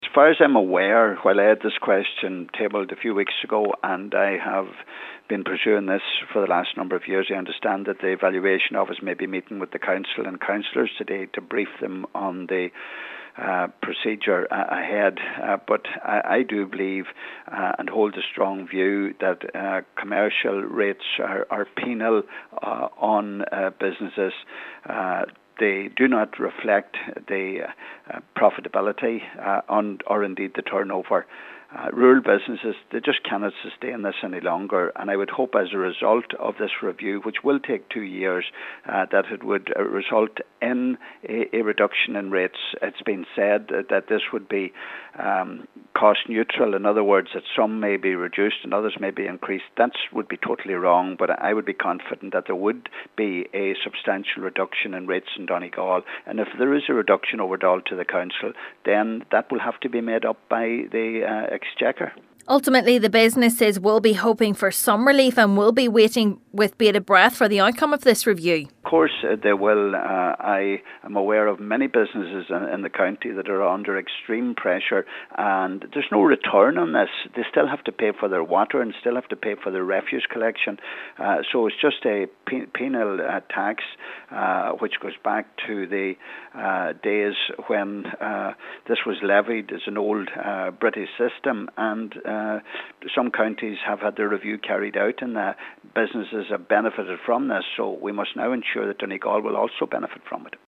Deputy Gallagher is hopeful the process, which will take two years to complete will provide some relief for rural businesses: